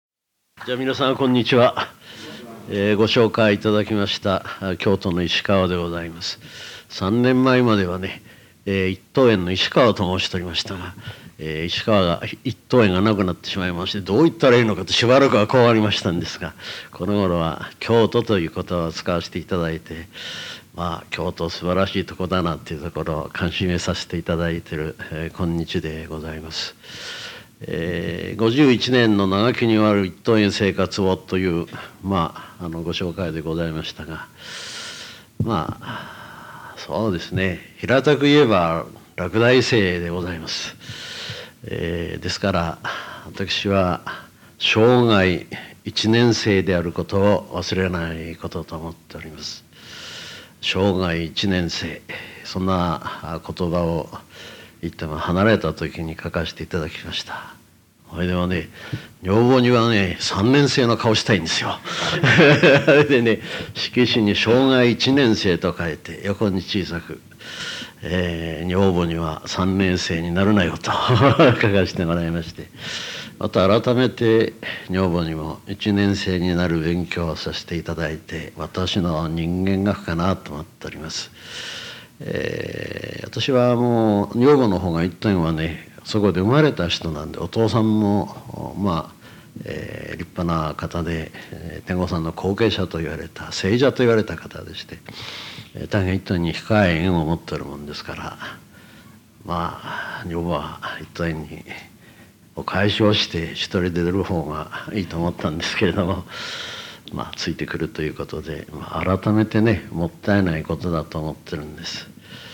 当時の録音状況により、若干聞き取りにくいところもございます。